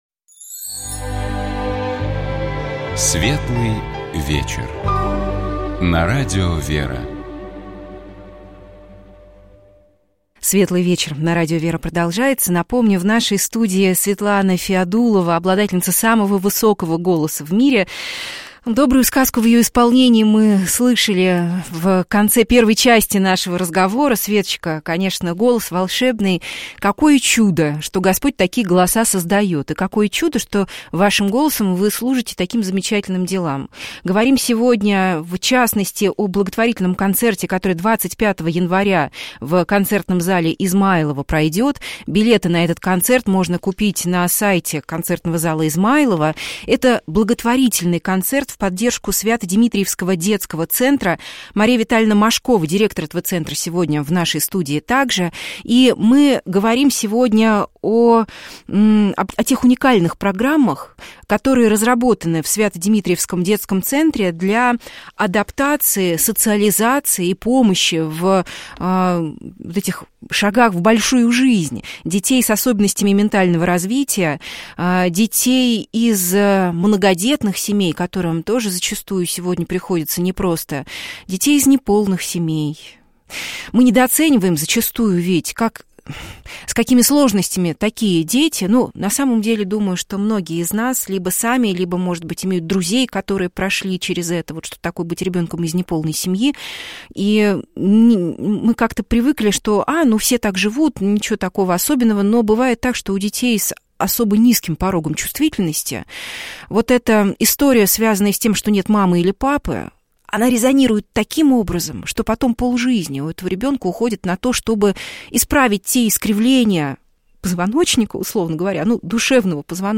Нашими гостями были оперная певица